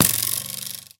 bowhit4.ogg